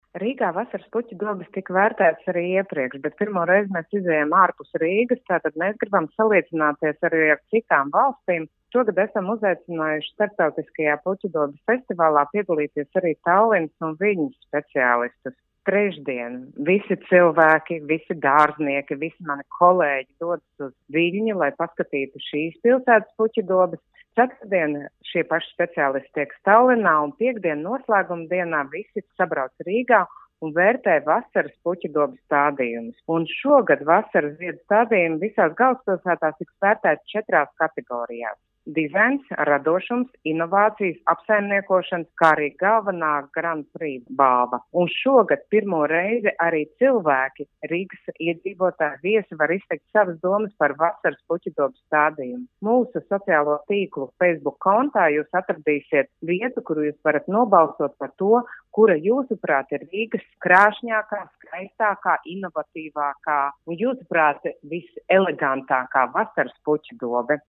RADIO SKONTO Ziņās par 1.Baltijas Starptautisko Vasaras puķu dobju festivālu